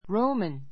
Roman róumən ロ ウマン 形容詞 （古代・現代） ローマの; ローマ人の 名詞 （古代・現代の） ローマ人, ローマ市民 When (you are) in Rome, do as the Romans do.